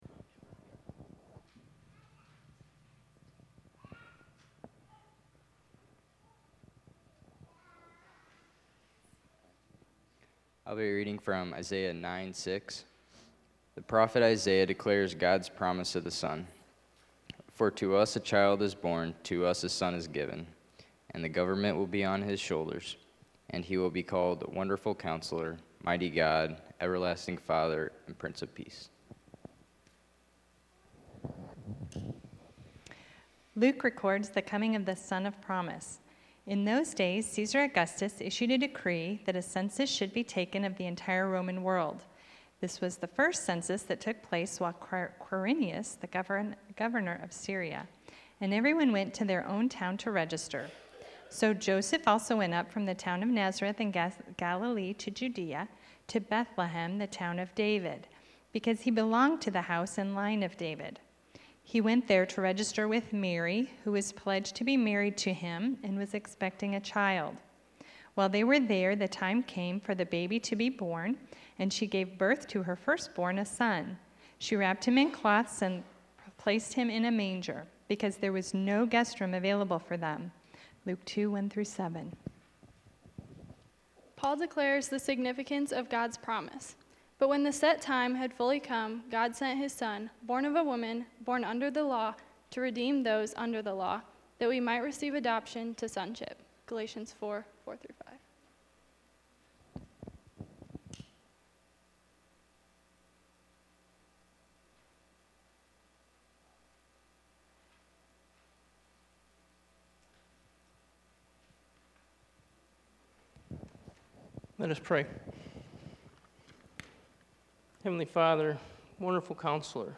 Sermon Sentence: God Sent His Son to Change Your Life in 3 Ways.